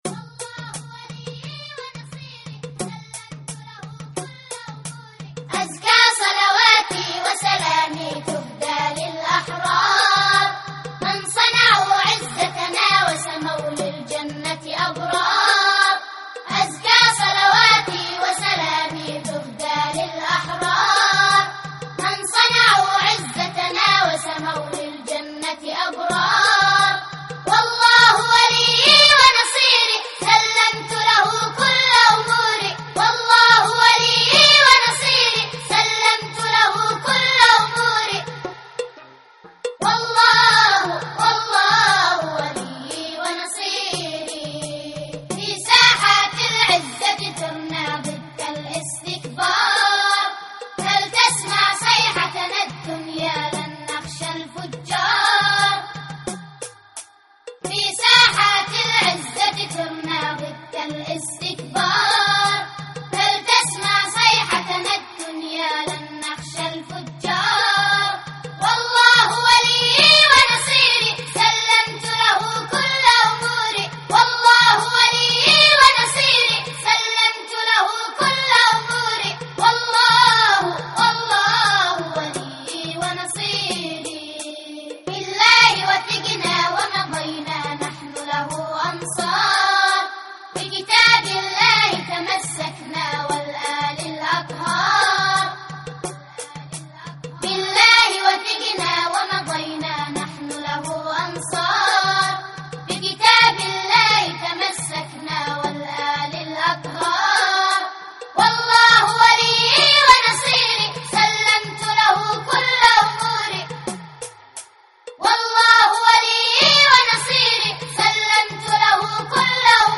اناشيد يمنية